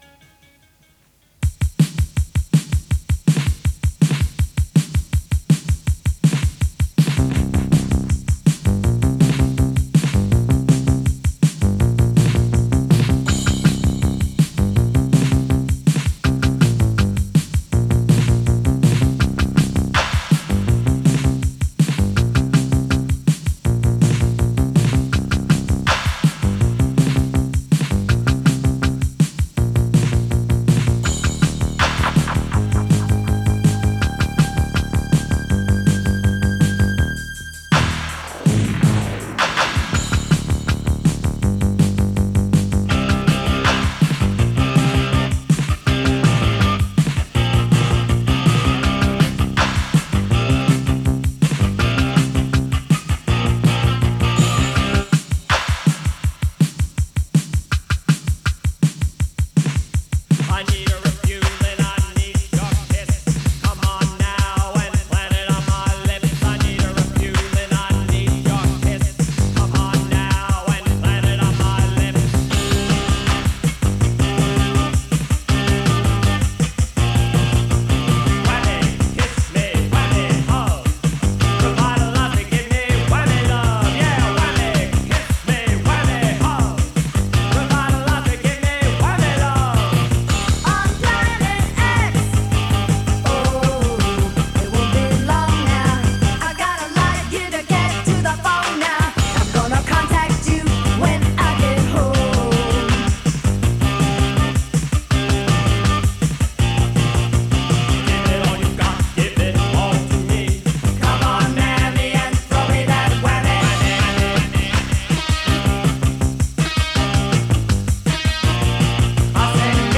类型：新浪潮 New Wave 媒体：MC [磁带TAPE]